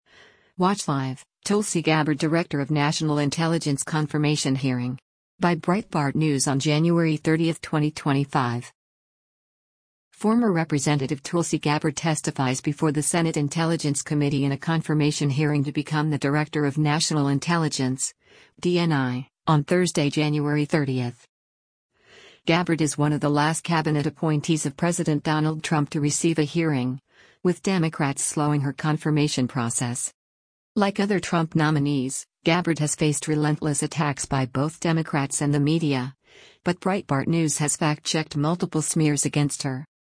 Former Rep. Tulsi Gabbard testifies before the Senate Intelligence Committee in a confirmation hearing to become the Director of National Intelligence (DNI) on Thursday, January 30.